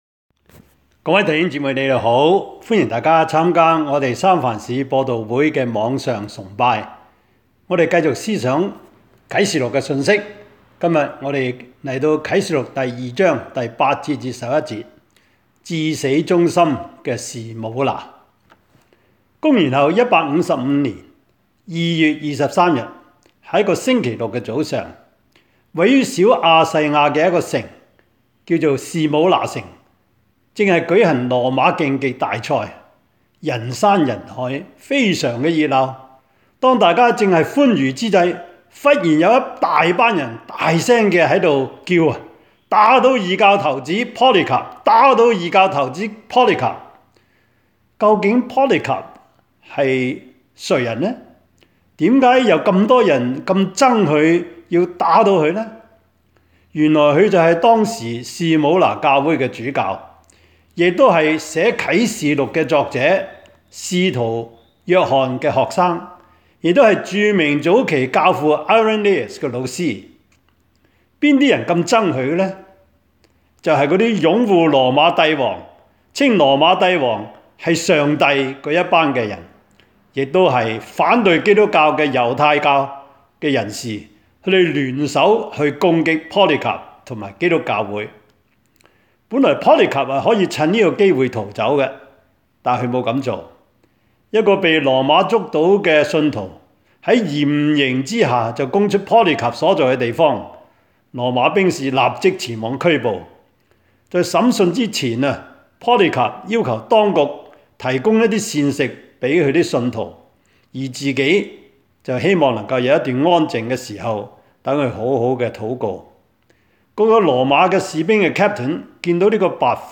Topics: 主日證道 « 誰降旨讓你們建造?